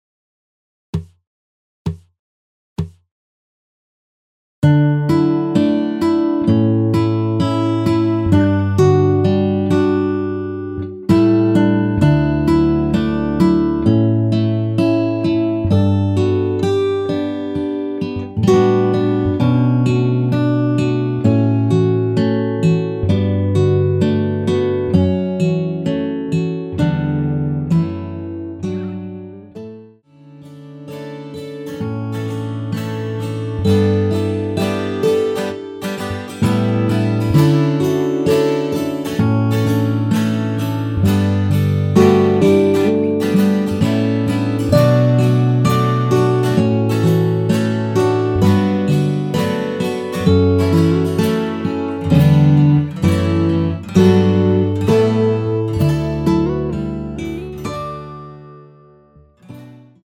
전주없이 시작 하는 곡이라 카운트 넣어 놓았습니다.(미리듣기 참조)
원키에서(-1)내린 MR입니다.
D
앞부분30초, 뒷부분30초씩 편집해서 올려 드리고 있습니다.
중간에 음이 끈어지고 다시 나오는 이유는